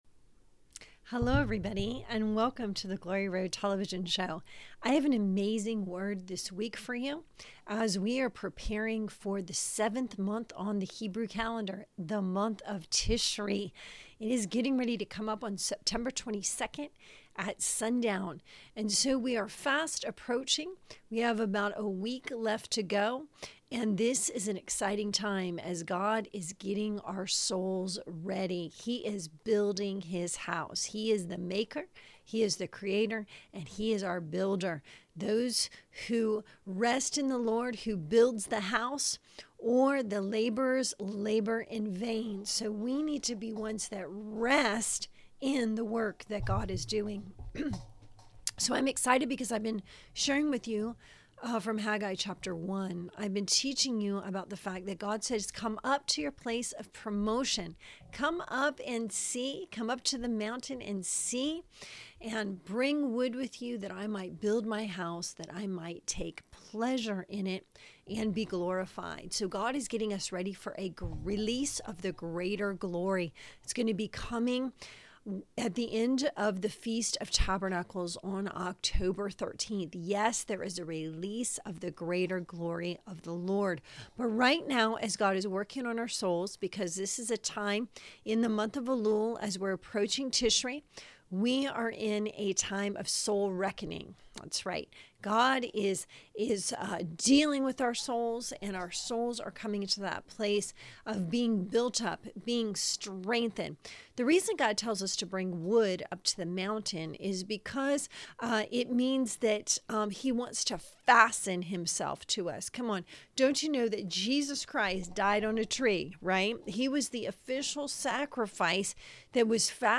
Get ready for a powerful prophetic teaching as we enter the Hebrew month of Tishri and prepare for the Feast of Trumpets on September 22 and the Feast of Tabernacles on October 13.